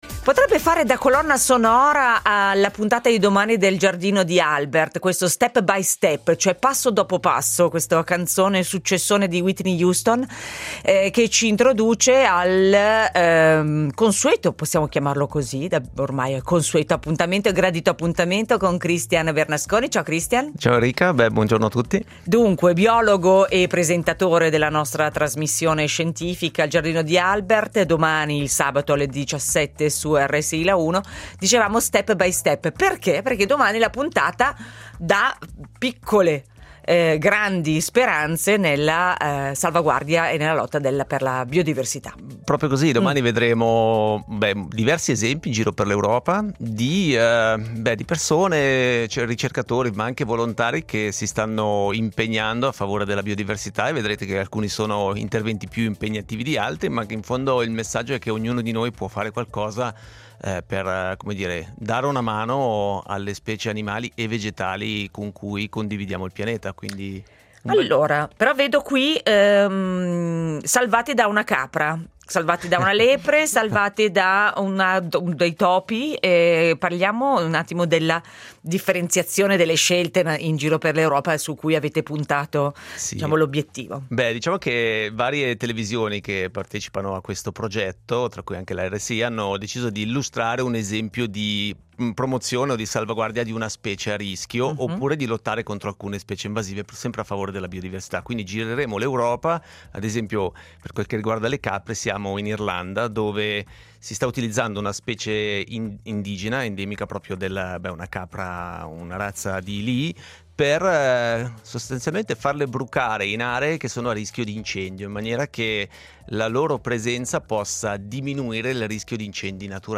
Domani al Giardino di Albert tante piccole iniziative per aiutare la biodiversità. In studio